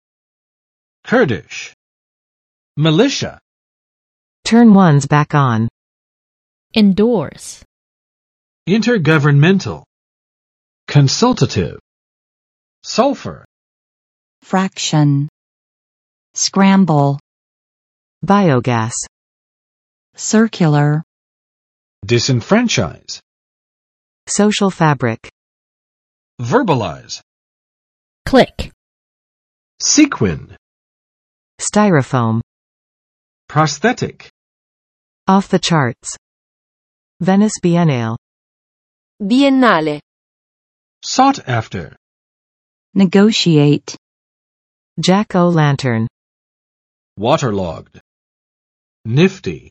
[ˋkɝdɪʃ] adj. 库德人的
Kurdish.mp3